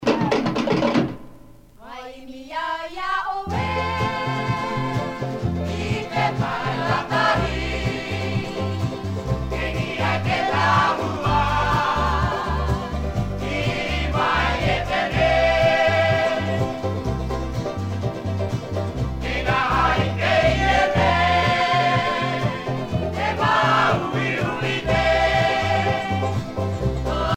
Groupe folklorique